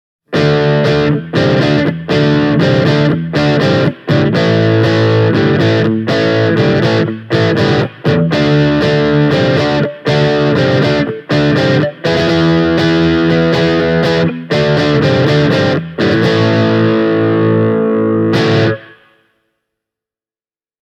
Kitarasoundit soivat erittäin hyvin, ja myös BEAM:n efektitarjonta on varsin maukas:
blackstar-id_core-beam-e28093-crunch.mp3